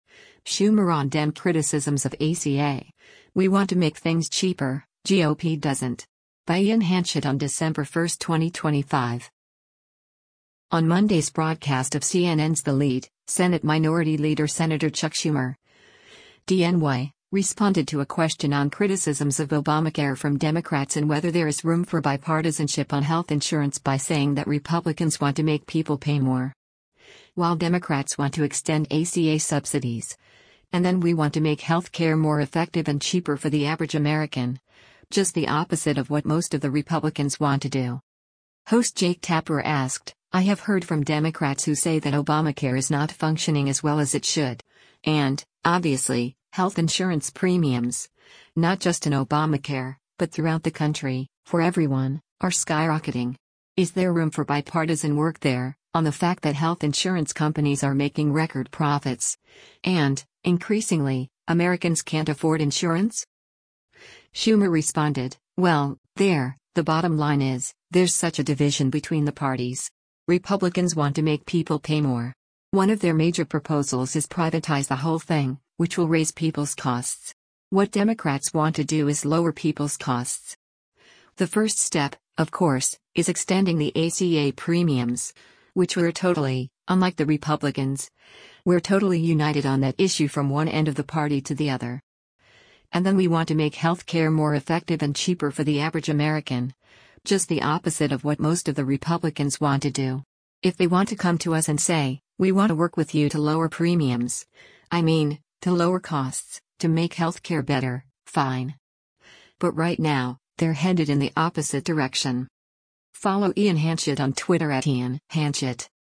On Monday’s broadcast of CNN’s “The Lead,” Senate Minority Leader Sen. Chuck Schumer (D-NY) responded to a question on criticisms of Obamacare from Democrats and whether there is room for bipartisanship on health insurance by saying that “Republicans want to make people pay more.”